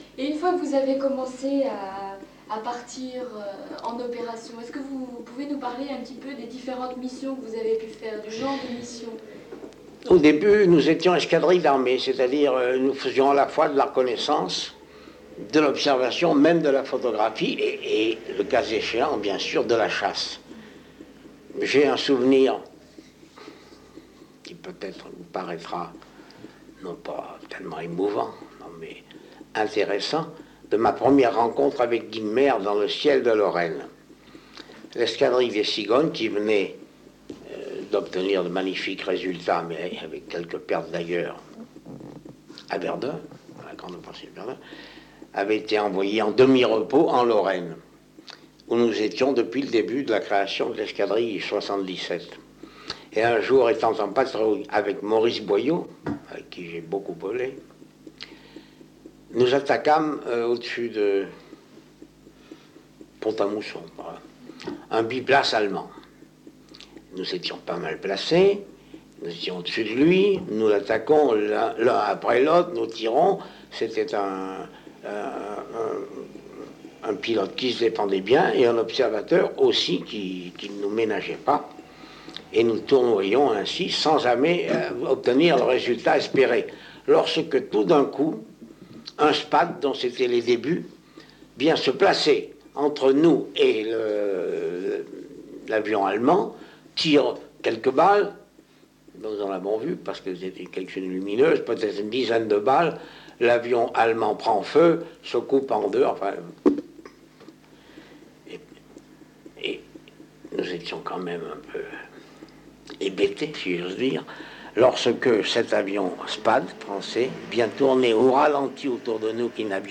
Entretien réalisé le 20 janvier 1976 à Royat (Puy-de-Dôme)